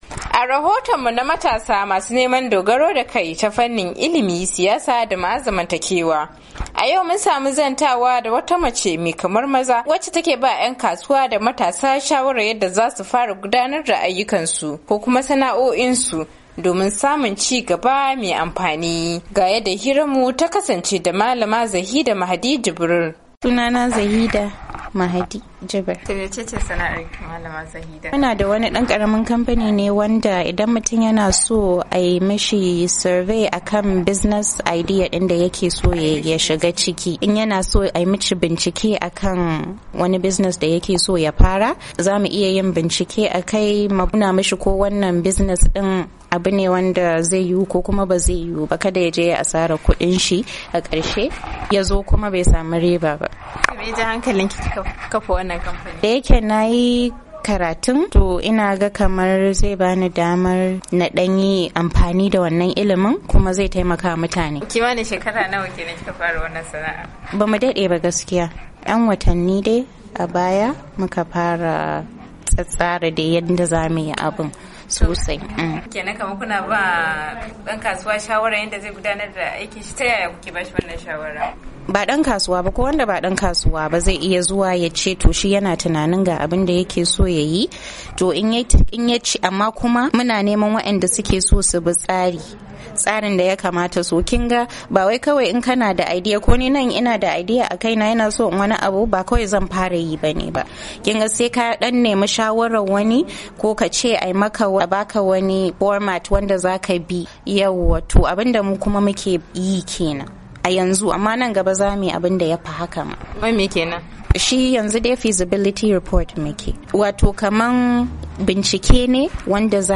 Ga wakiliyar Dandalin VOA da Karin bayani.